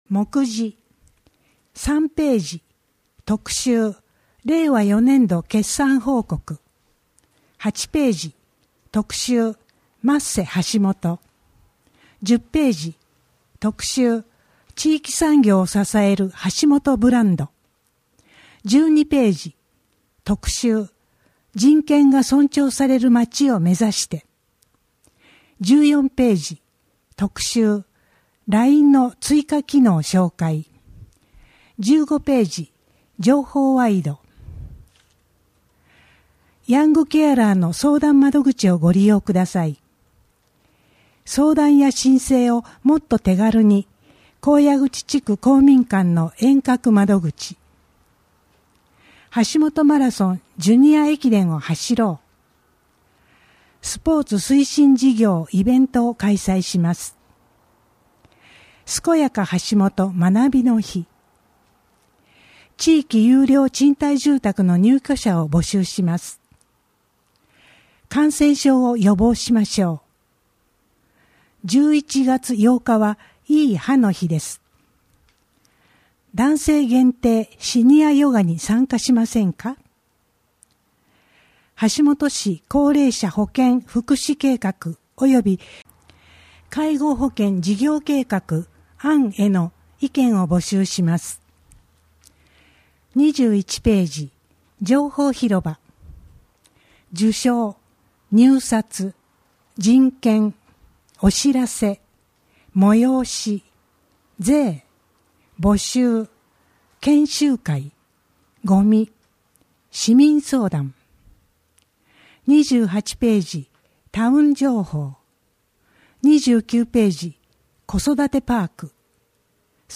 WEB版　声の広報 2023年11月号